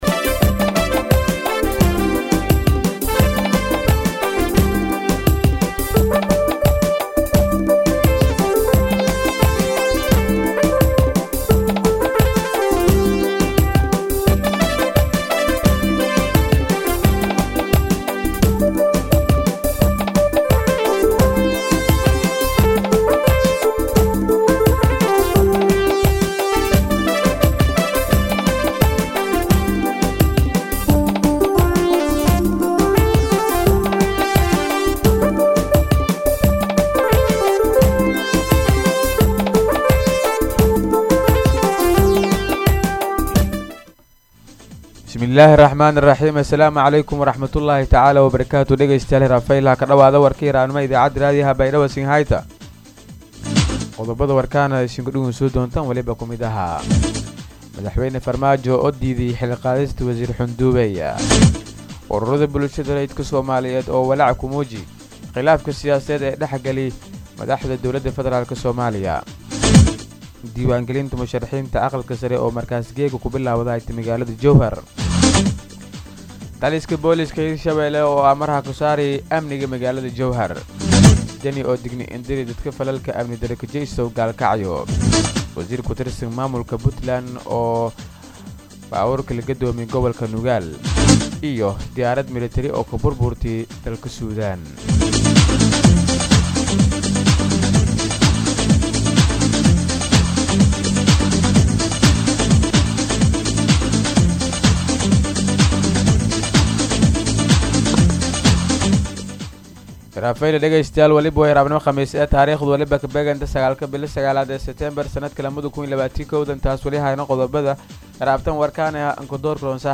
DHAGEYSO:-Warka Subaxnimo Radio Baidoa 9-9-2021